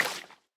wet_grass2.ogg